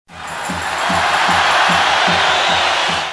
1 channel
Title=foule_acclam01